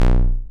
error.mp3